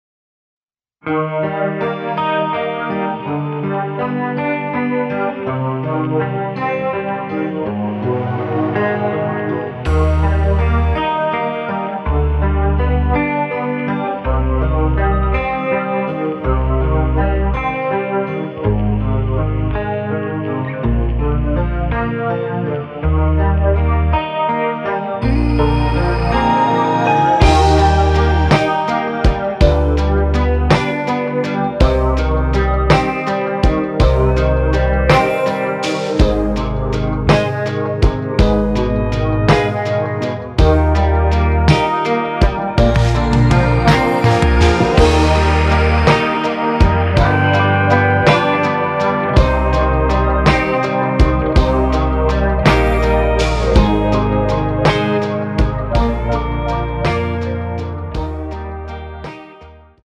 Eb
앞부분30초, 뒷부분30초씩 편집해서 올려 드리고 있습니다.
중간에 음이 끈어지고 다시 나오는 이유는